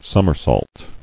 [sum·mer·sault]